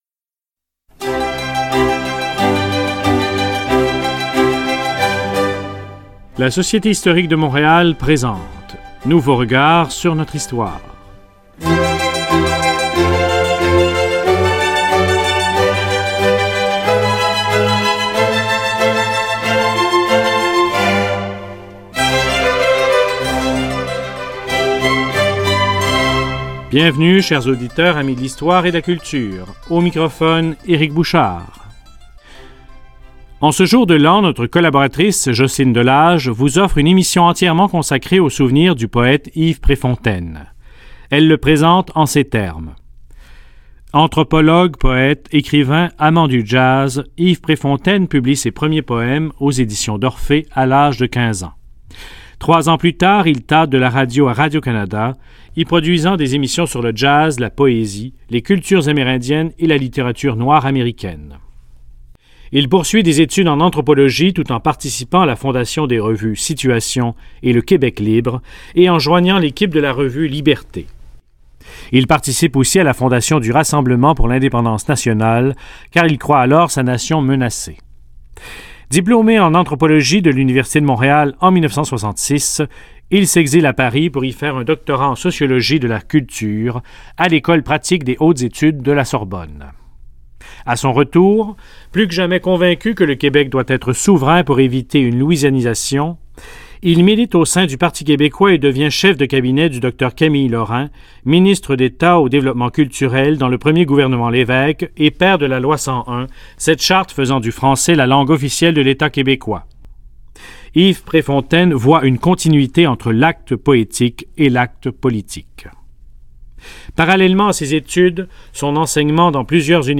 On vous invite également à écouter cette entrevue accordée en 2011 par monsieur Préfontaine à Radio VM dans le cadre de l’émission Nouveaux regards sur notre histoire